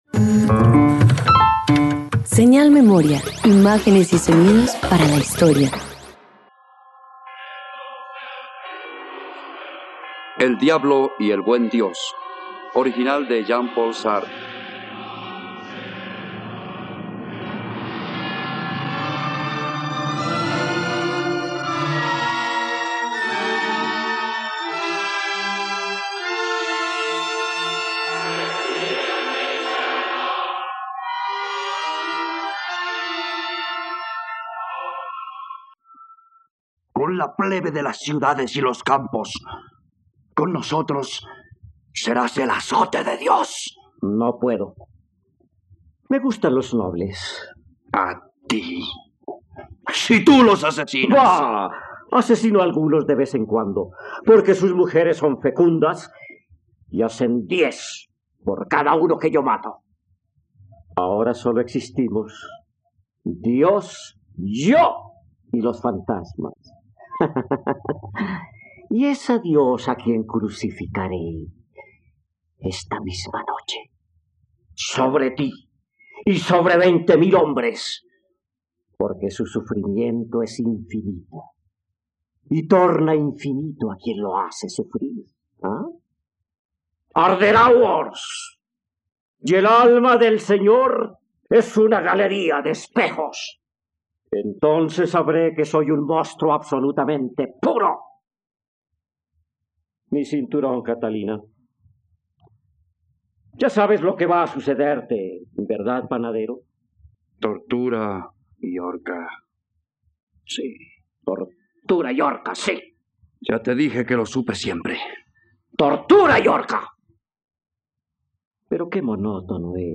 ..Radioteatro. Escucha “El diablo y el buen Dios”, una adaptación radiofónica basada en la obra de Jean Paul Sartre.